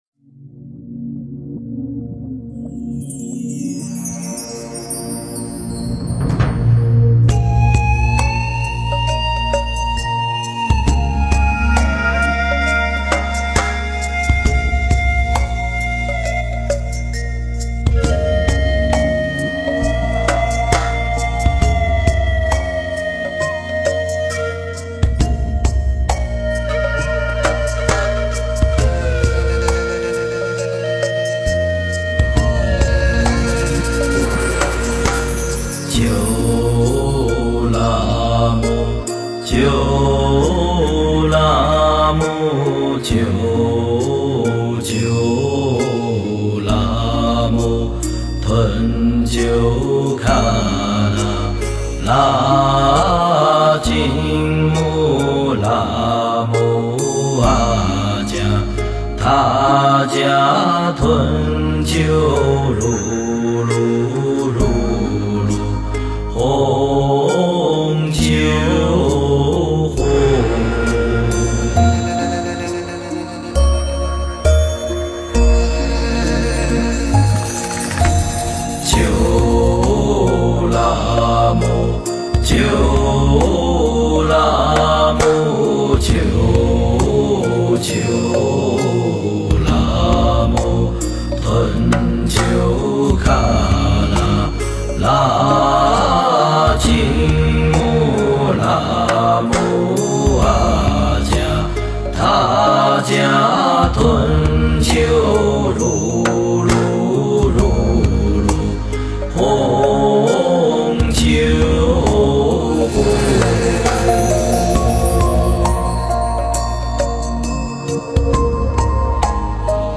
佛音 诵经 佛教音乐 返回列表 上一篇： 回向偈 下一篇： 人生一首歌 相关文章 法句经-爱欲品（念诵） 法句经-爱欲品（念诵）--未知...